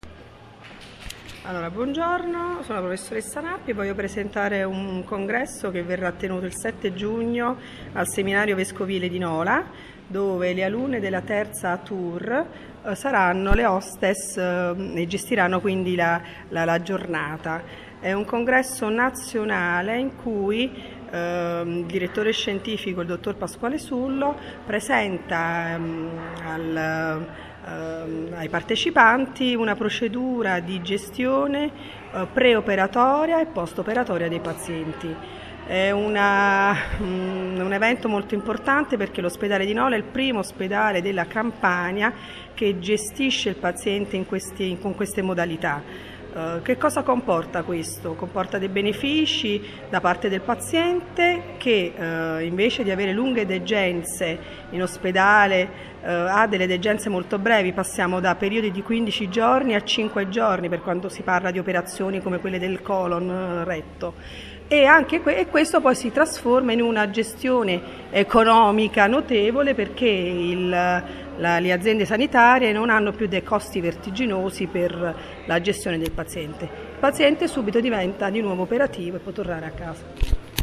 La testimonianza audio